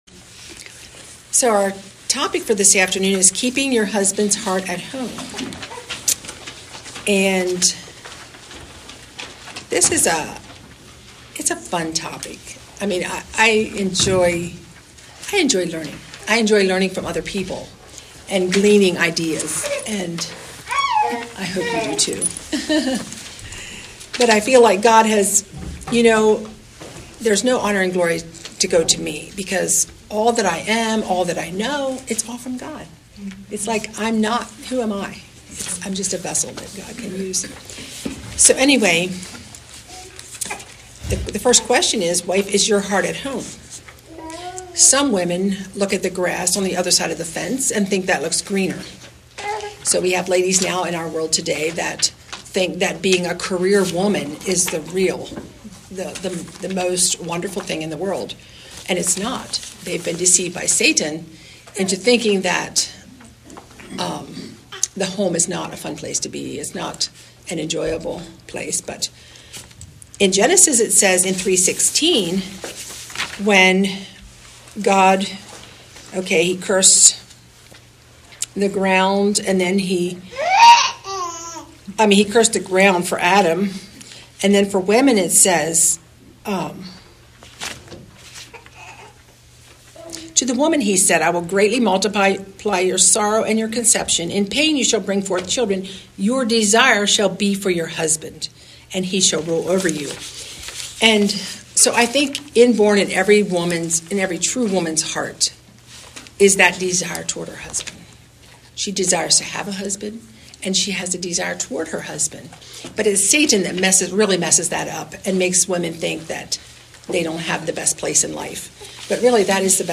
Topic: Family , Wives Service Type: Ladies Seminar